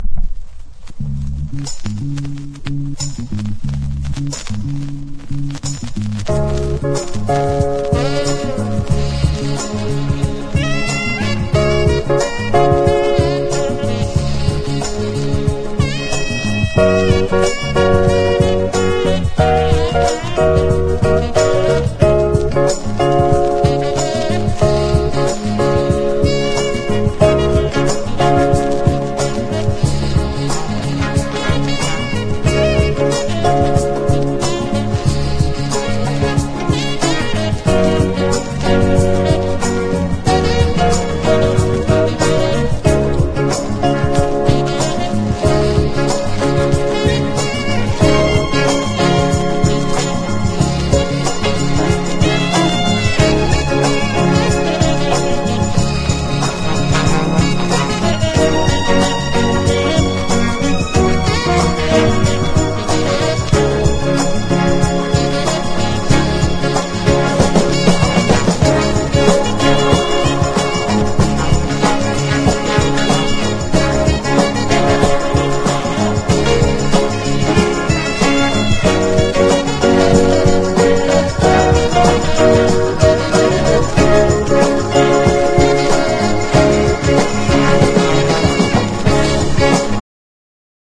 # FUNK / DEEP FUNK# NORTHERN / MODERN